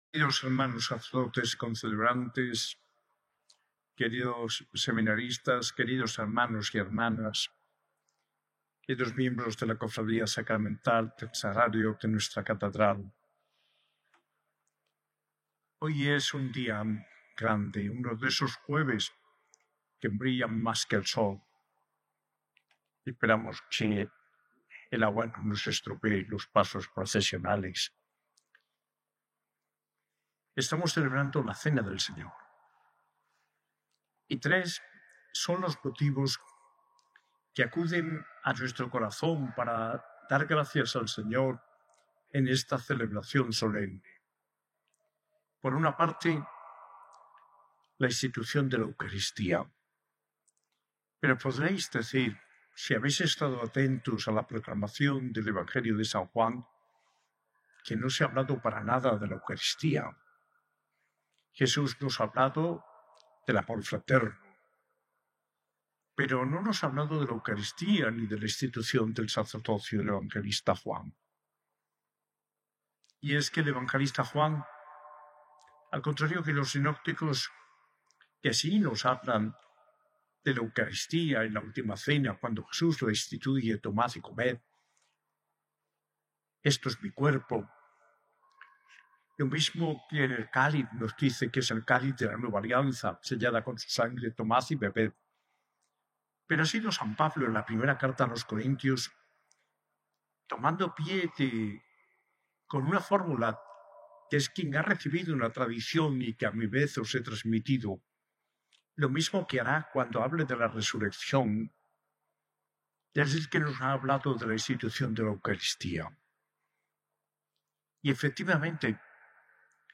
Homilía del arzobispo de Granada, Mons. José María Gil Tamayo, en la Misa de la Cena del Señor, el 17 de abril de 2025, en la S.A.I Catedral.